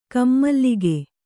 ♪ kammallige